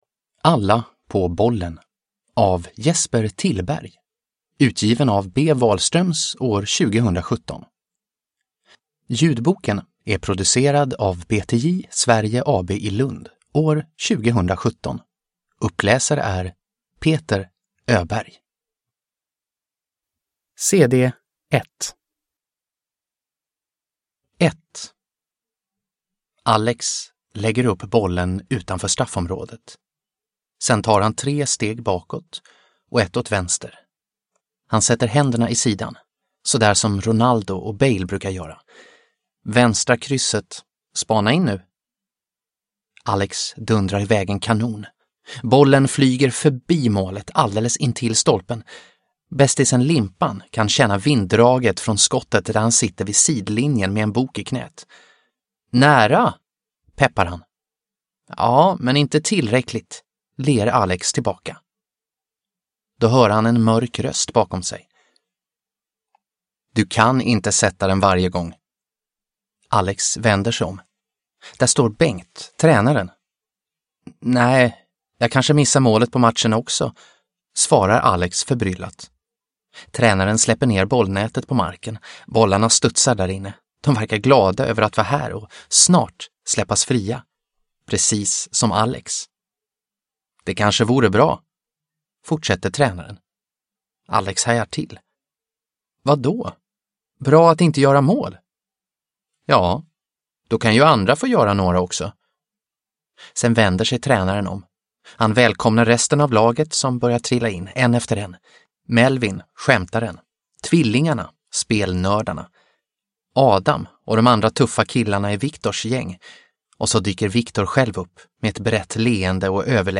Alla på bollen – Ljudbok – Laddas ner